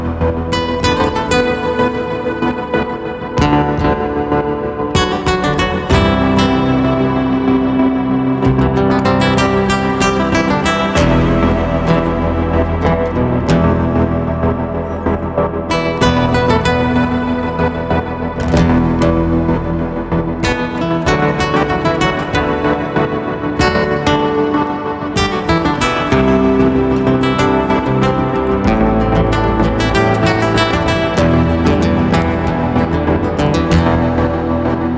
# Instrumental